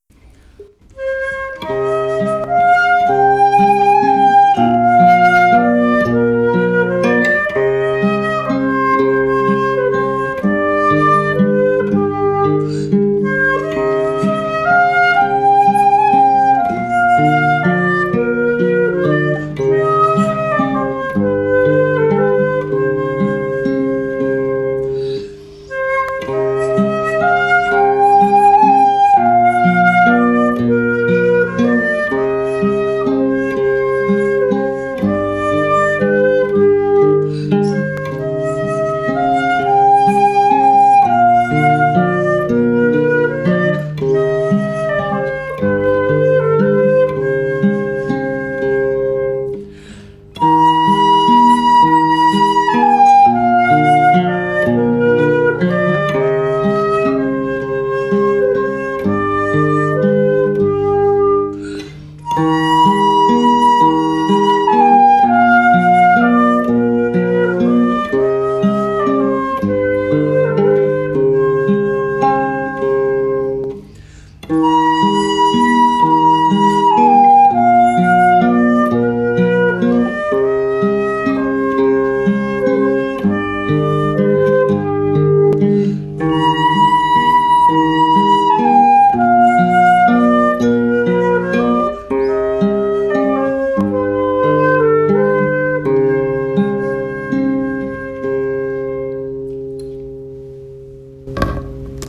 When Flute and Guitar Come Together
We ordered some flute and classical guitar duets that we’ve been practicing.